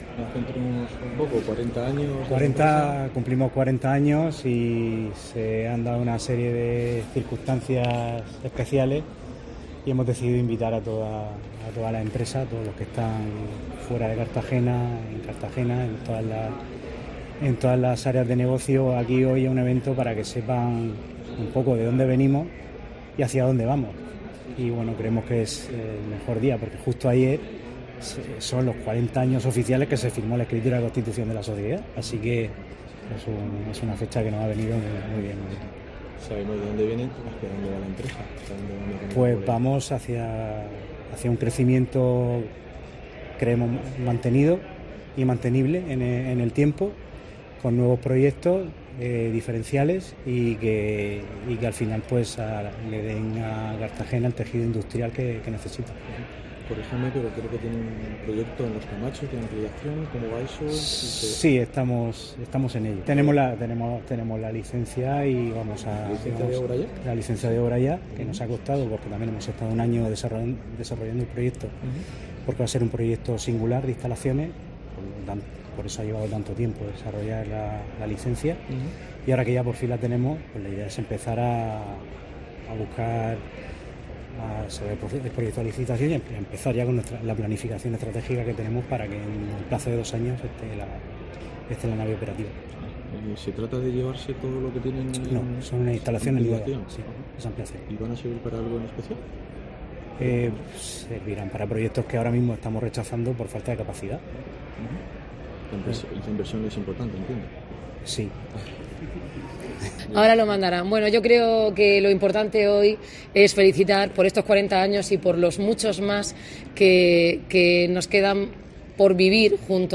Noelia Arroyo ha felicitado a la empresa en el acto conmemorativo en el que se ha anunciado el inicio de las obras de sus nuevas instalaciones